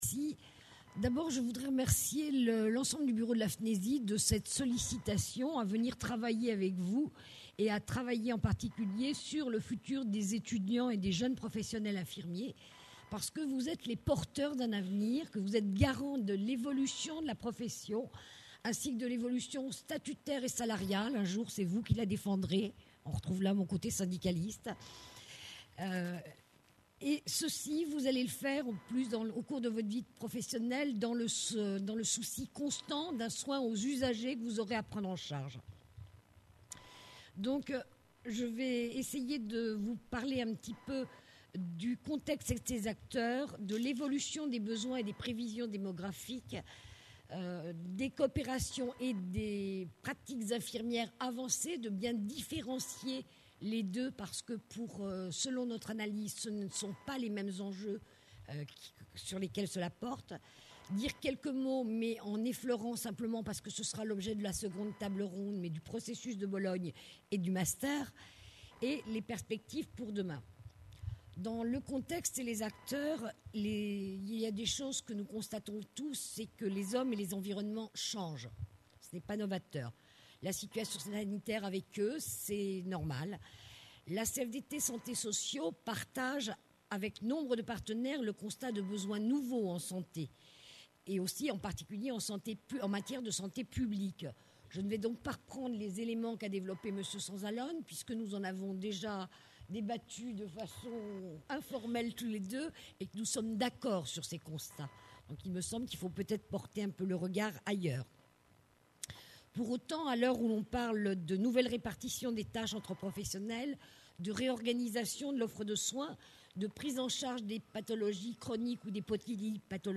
Conférence enregistrée lors 10ème Congrès National des Etudiants en Soins Infirmiers (FNESI) – Paris le 26 novembre 2010 - L’évolution générale de l’offre de soins.